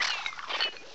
sovereignx/sound/direct_sound_samples/cries/sinistcha.aif at master